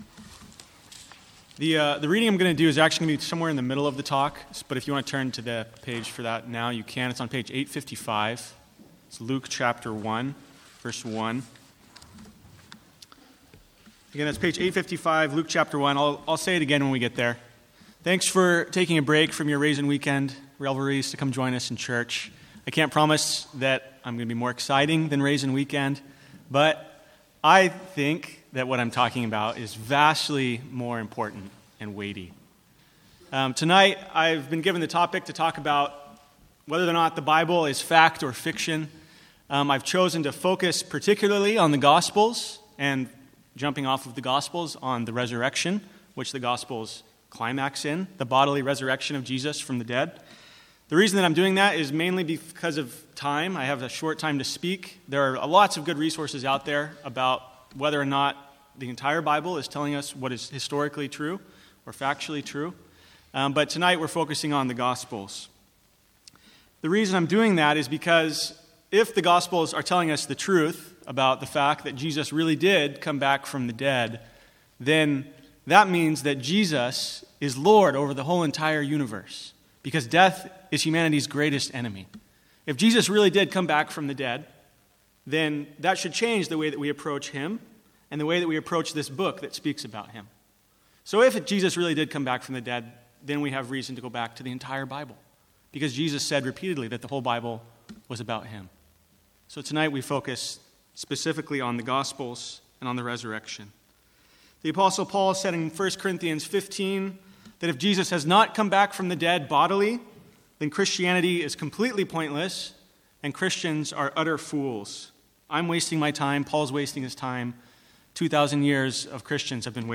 Sermons | St Andrews Free Church
From the Sunday evening 'Tough Questions' guest series.